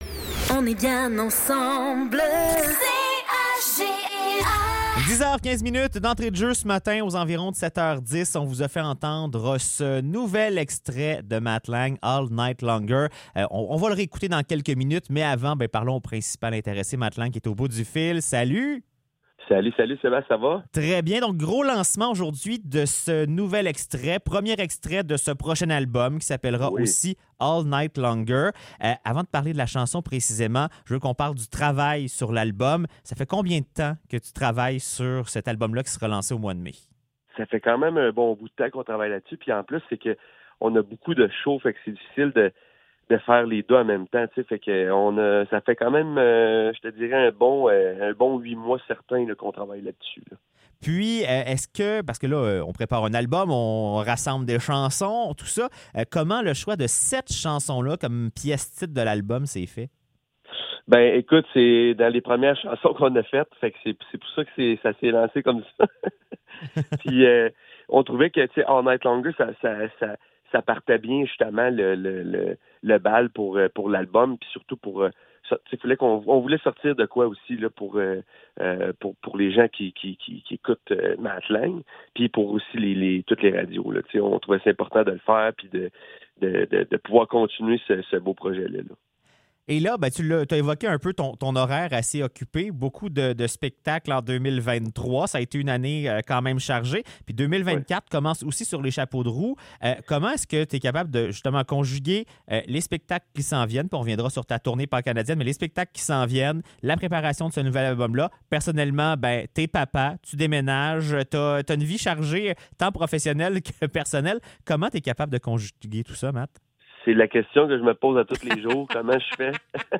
Entrevue avec Matt Lang
entrevue-avec-matt-lang-5-janvier-2023-.mp3